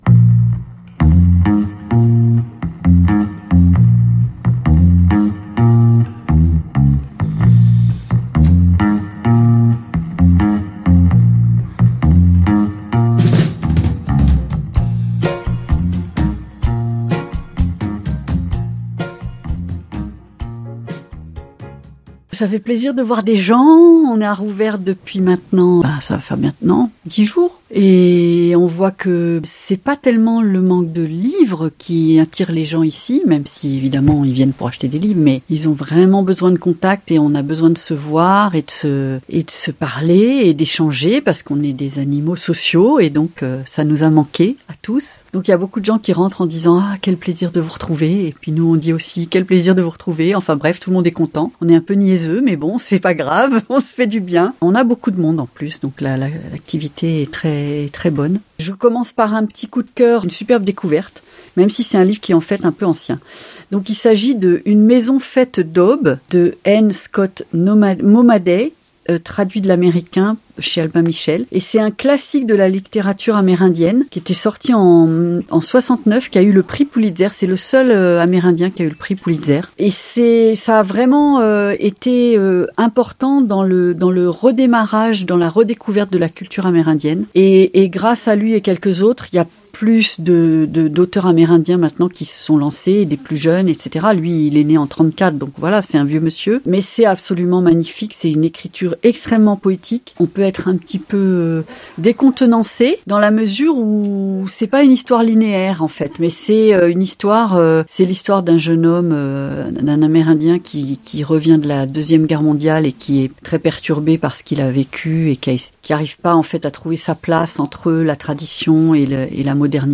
Elle nous livre quelques coups de coeur très convaincants pour des romans que nous vous laissons découvrir durant cette entrevue de première nécessité...